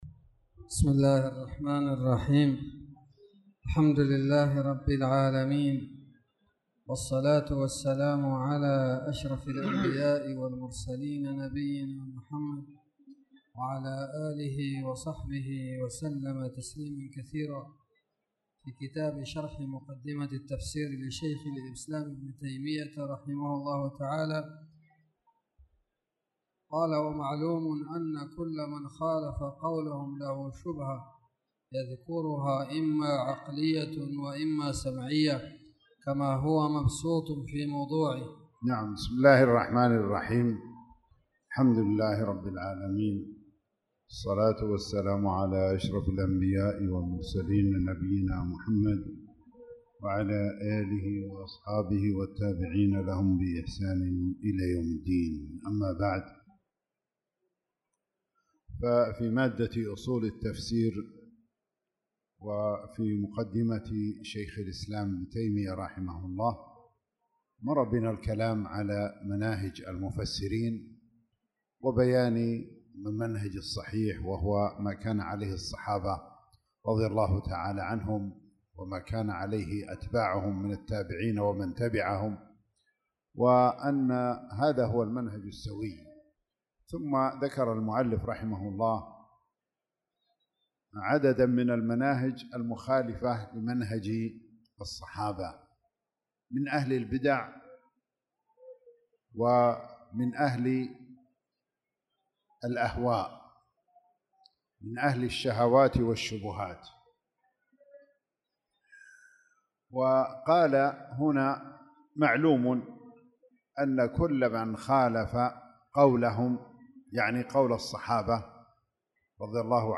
تاريخ النشر ١٥ شعبان ١٤٣٧ هـ المكان: المسجد الحرام الشيخ